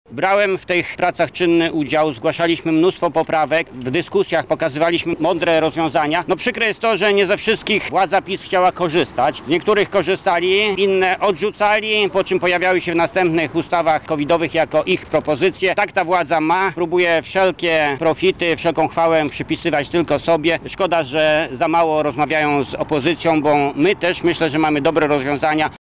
Było bardzo dużo ustaw w sejmie i senacie, które miały bronić polską gospodarkę i polskich przedsiębiorców przed skutkami covidu – mówi senator Jacek Bury: